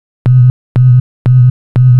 TSNRG2 Off Bass 021.wav